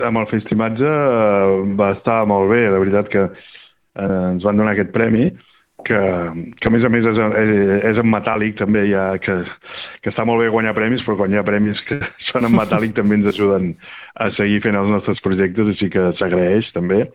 En una entrevista a RCT